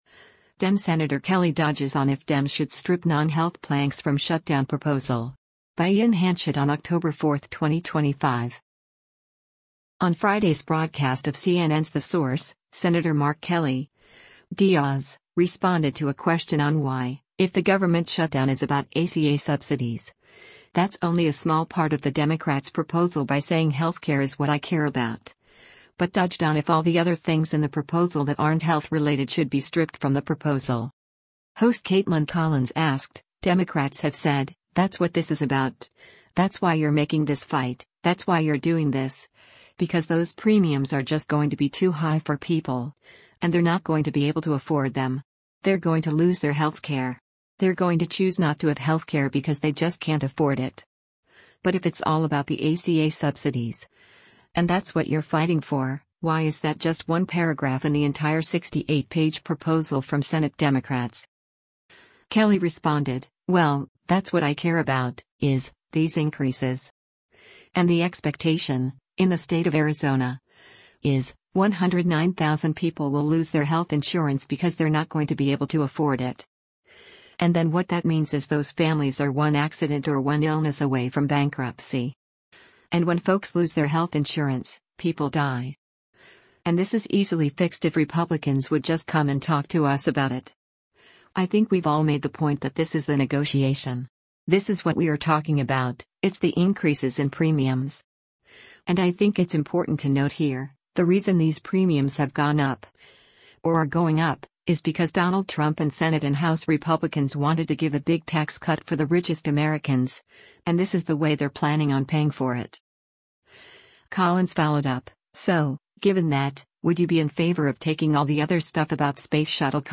On Friday’s broadcast of CNN’s “The Source,” Sen. Mark Kelly (D-AZ) responded to a question on why, if the government shutdown is about ACA subsidies, that’s only a small part of the Democrats’ proposal by saying health care is “what I care about,” but dodged on if all the other things in the proposal that aren’t health-related should be stripped from the proposal.